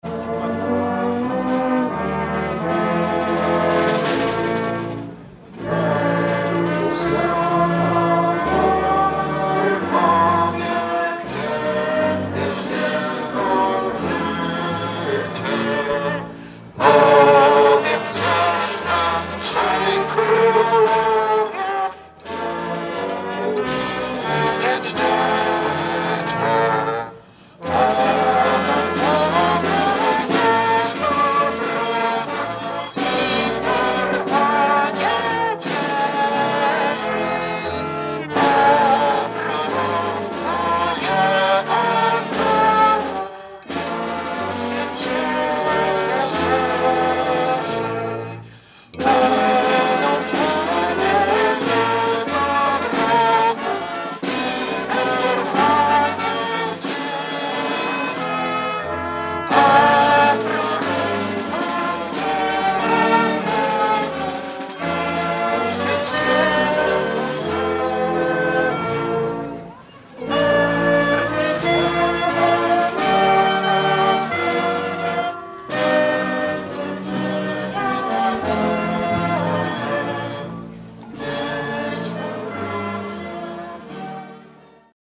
Po raz 13. przez Wrocław przeszedł Orszak Trzech Króli.
Udział w orszaku wzięła orkiestra wrocławskiej Policji.